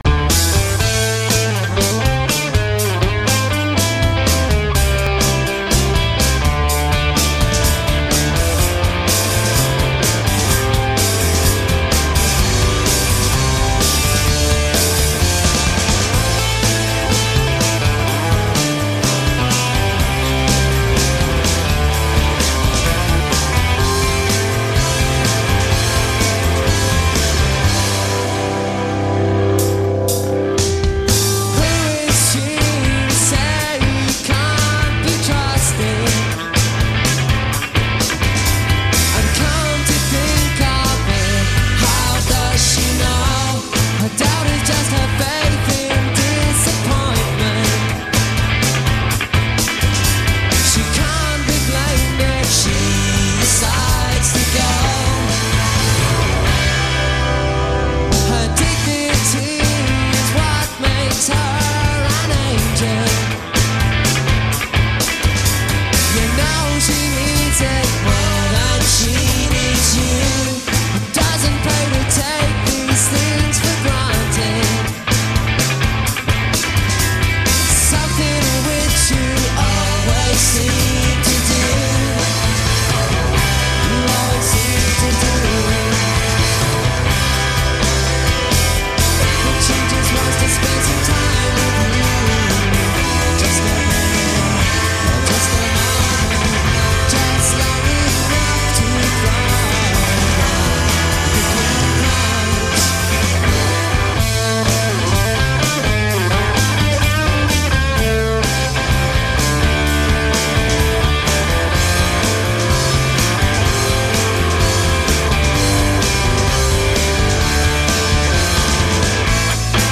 recorded live on July 5, 1995.